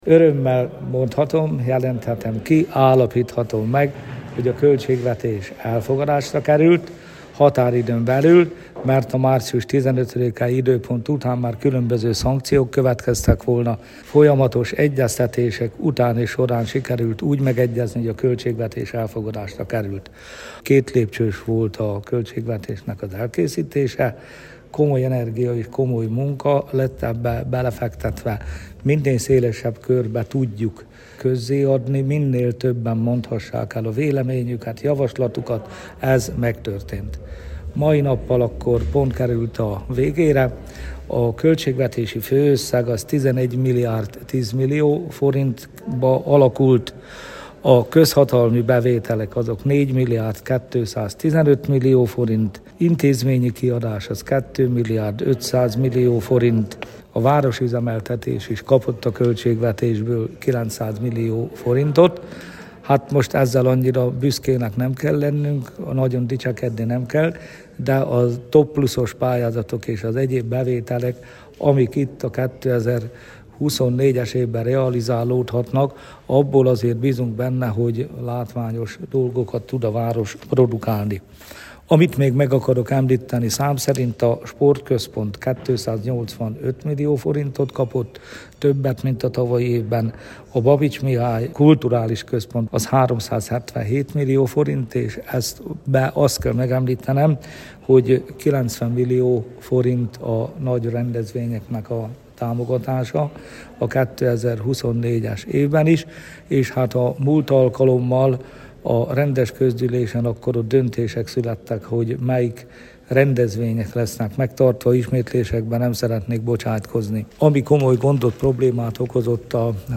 (Megszólal: Gyurkovics János, alpolgármester)